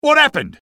sniper_paincrticialdeath04.mp3